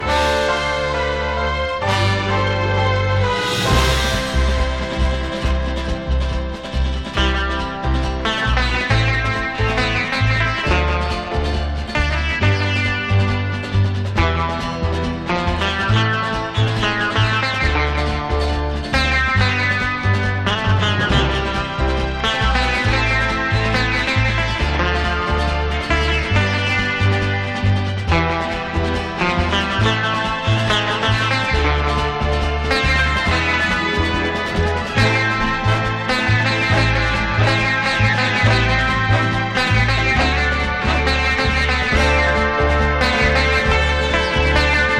Jazz, Pop, Easy Listening　USA　12inchレコード　33rpm　Stereo